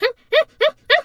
pgs/Assets/Audio/Animal_Impersonations/hyena_laugh_short_08.wav at master
hyena_laugh_short_08.wav